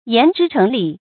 注音：ㄧㄢˊ ㄓㄧ ㄔㄥˊ ㄌㄧˇ
讀音讀法：